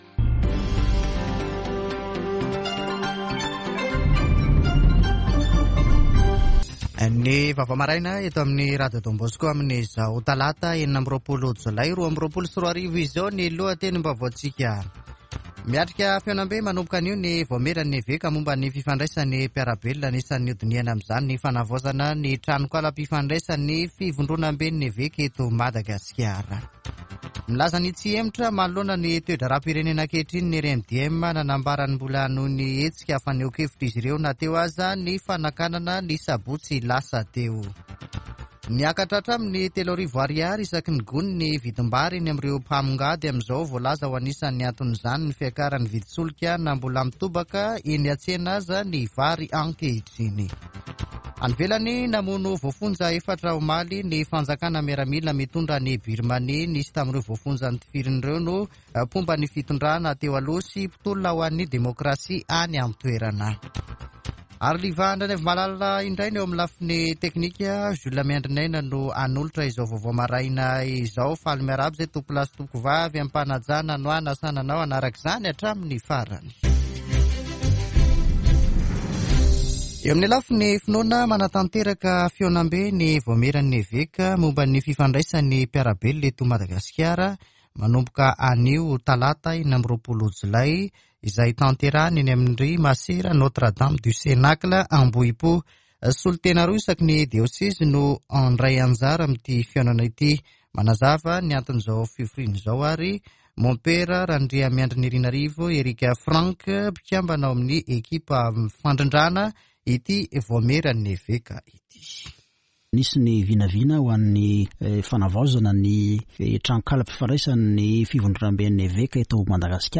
[Vaovao maraina] Talata 26 jolay 2022